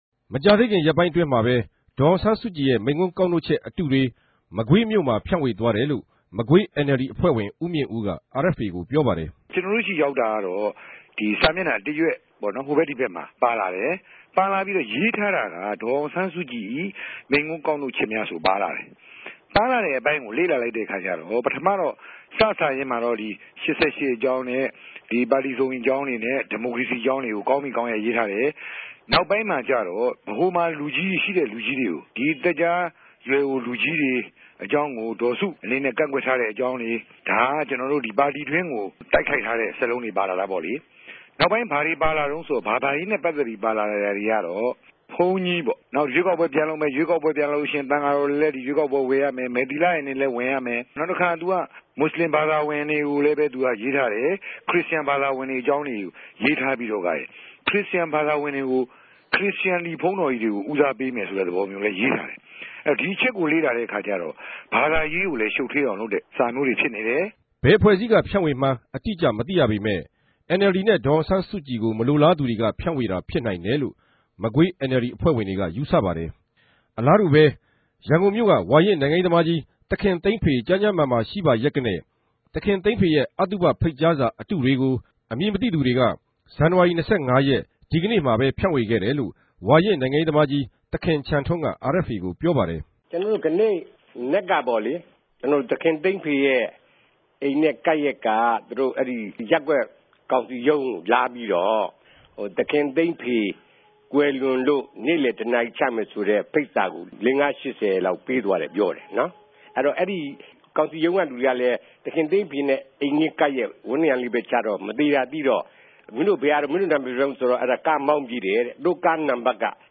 ဗန်ကောက် RFA႟ုံးခြဲကနေ အစီရင်ခံထားပၝတယ်။